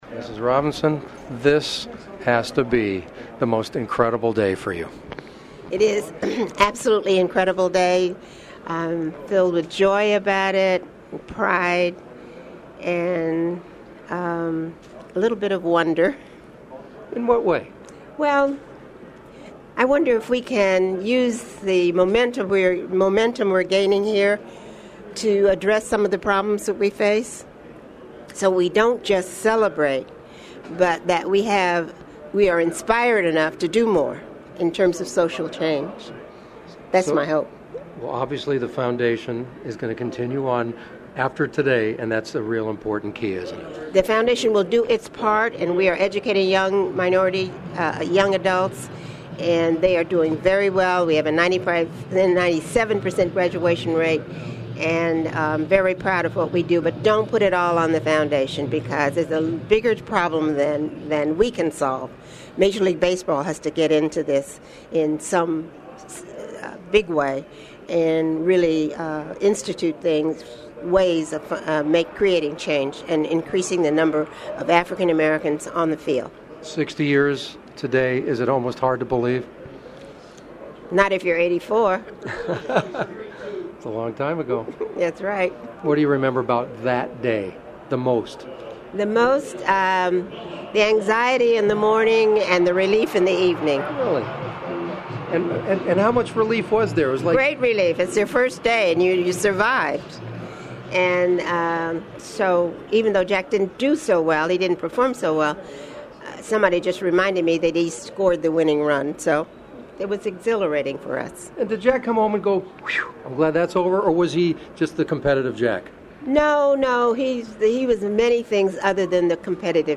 Mrs. Robinson did 3 interviews that day…one during the ESPN telecast, one between innings of our KFWB broadcast, and the other with little ‘ol me in the walkway behind the studio where another legendary Brooklyn Dodger does his work–Vin Scully. The following is our brief sitdown chat which included Rachel’s recollection of the first day that Jack played for the Dodgers in Ebbett’s Field…